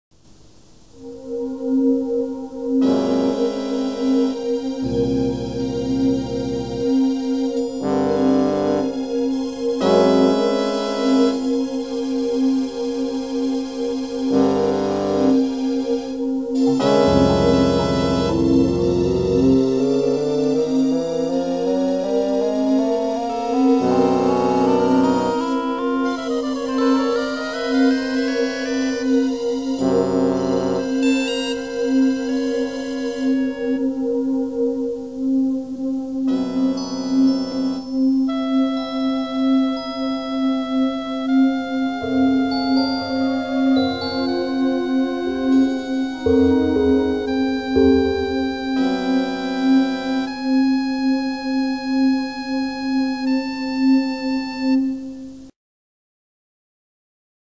Daraus ergibt sich eine rhythmische Struktur eines 4/4 Taktes, die als horizontales Raster über die gesamten Fassaden gelegt wird.
Dies ergibt eine temperierte Rasterung von 4 Oktaven und zwei Halbtönen.